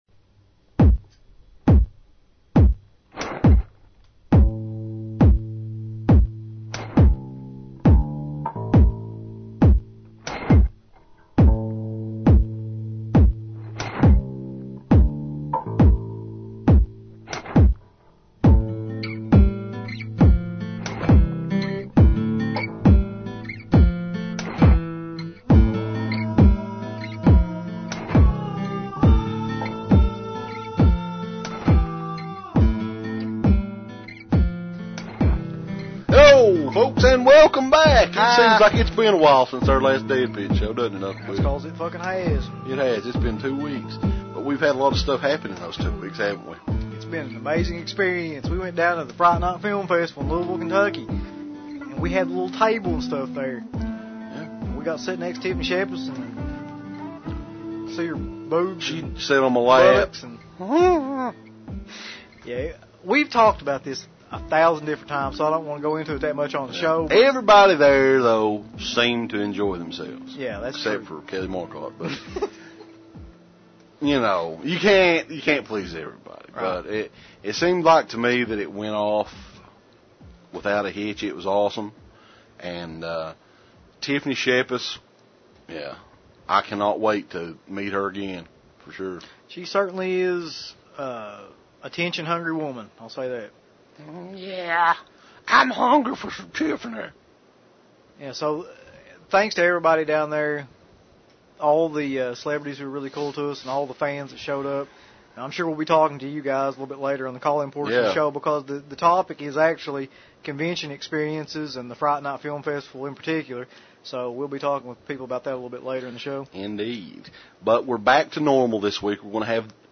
August 24th, 2007 The big show is here folks...with the man, the myth, the legend...John Carpenter is our guest this week on DEADPIT Radio in part one of our two part interview.
Plus we take your phonecalls on some of your favorite convention experiences and get feedback from the Fright Night Film Fest this past weekend in Louisville!